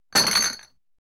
health lost.mp3